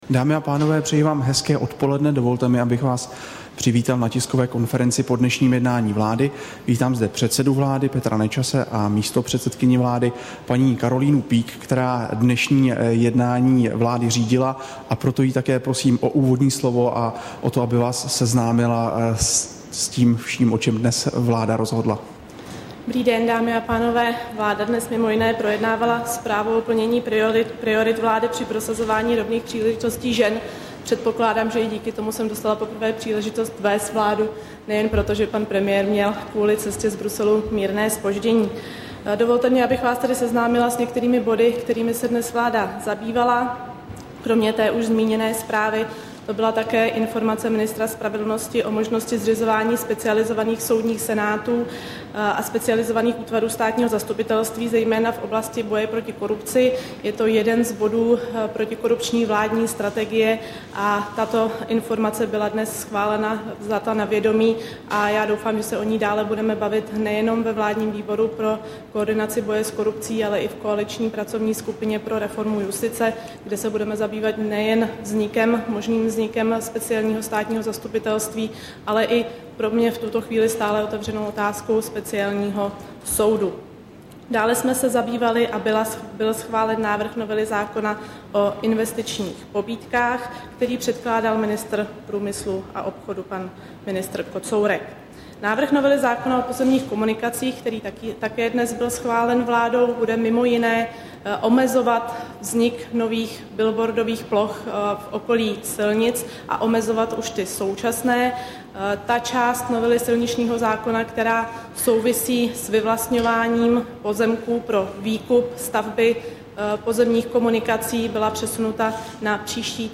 Tisková konference po jednání vlády, 19. října 2011